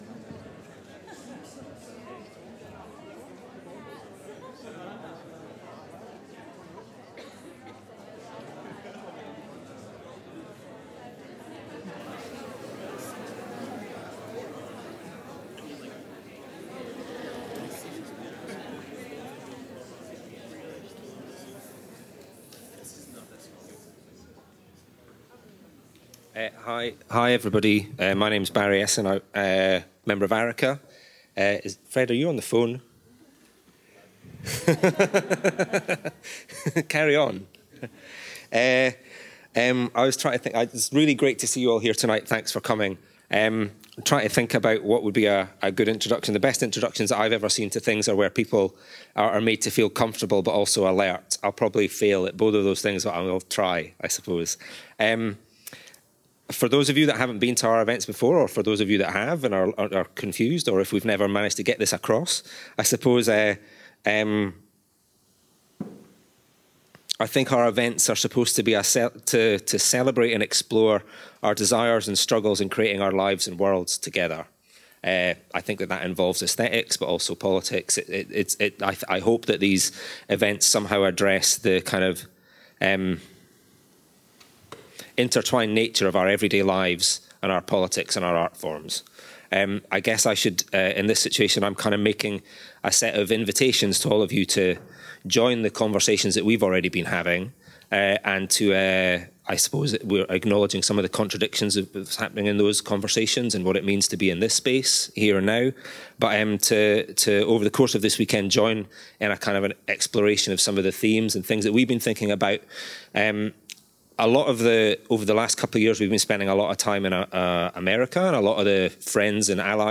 A socio-poetic reading on wayward communities – The wayward create upheavals, incite tumult. They come and go as they please; they are fugitive; they are in open rebellion against society.